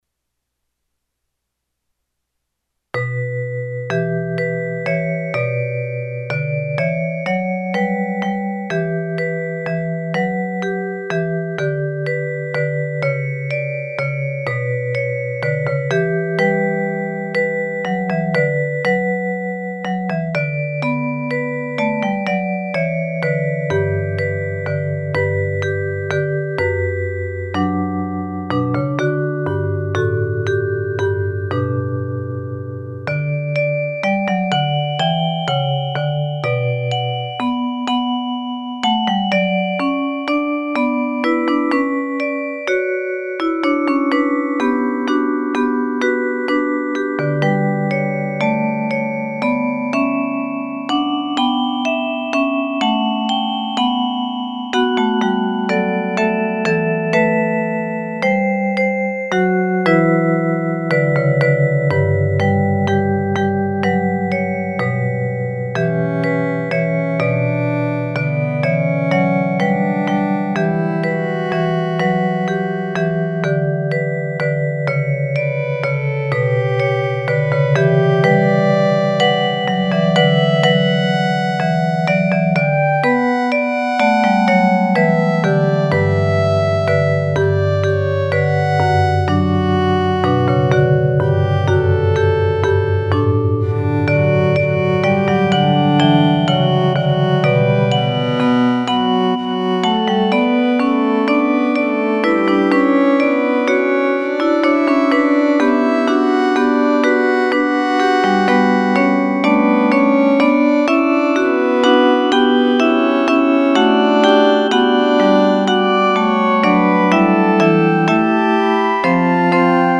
The upper part features the above mentioned hymn with slight changes, the oldest extant piece of music of the western world, composed in honor of Apollo around 138 BC and found carved on stone at the sanctuary of Delphi. the other parts are by myself.